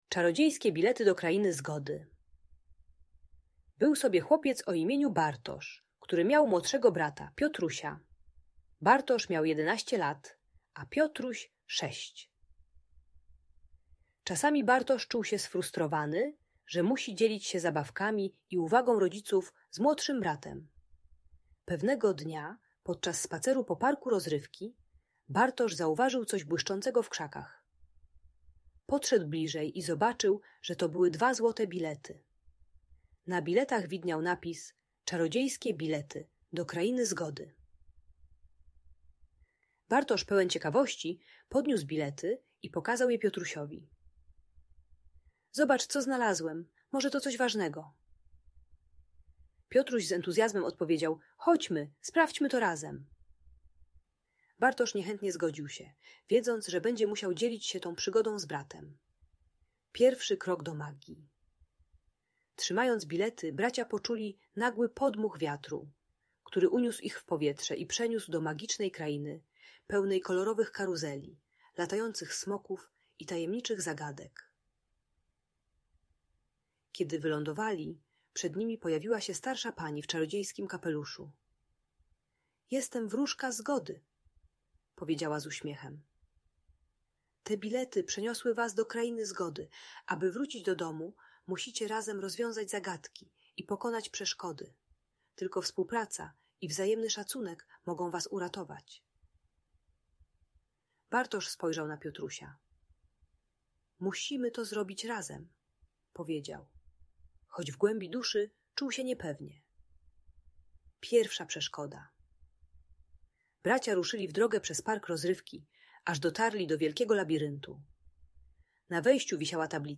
Czarodziejskie Bilety do Krainy Zgody - Bajkowa Historia - Audiobajka dla dzieci